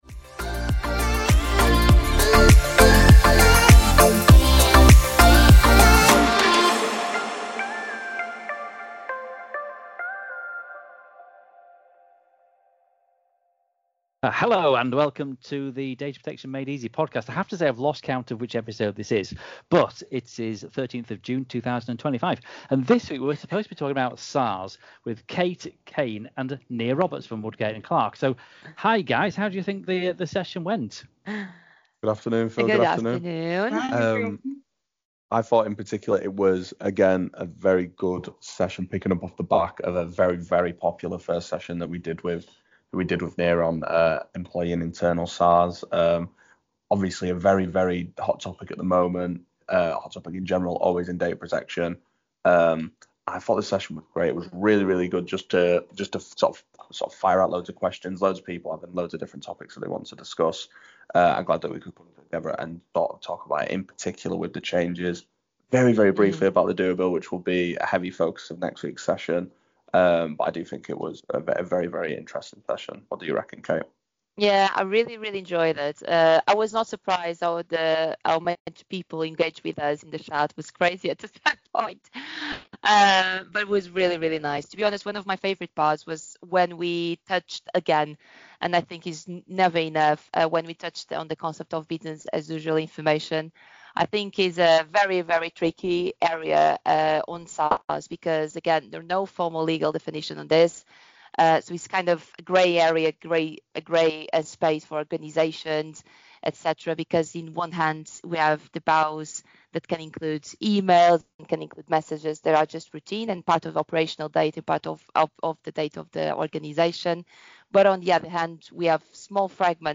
Our expert panel shares real-life examples, lessons from our recent in-person SAR workshop, and practical tips to help organisations navigate these often sensitive and challenging requests with confidence and compliance.